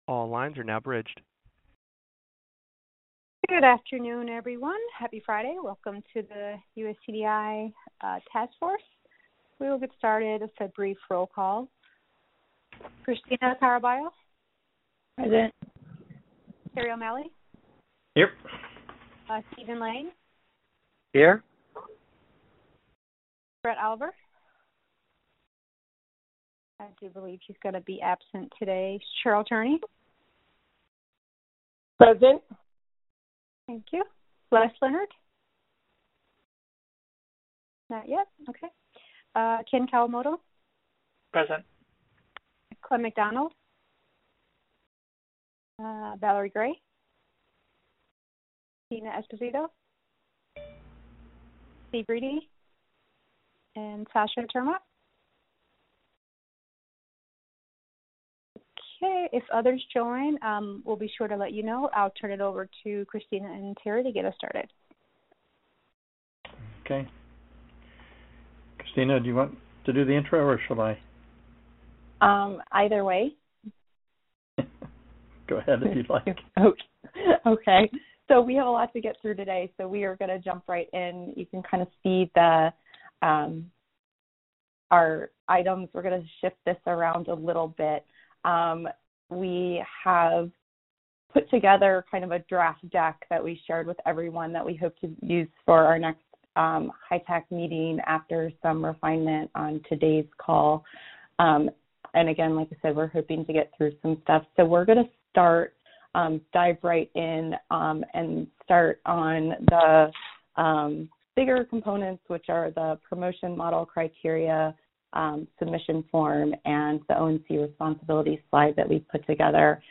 U.S. Core Data for Interoperability (USCDI) Task Force Meeting Audio 9-6-2019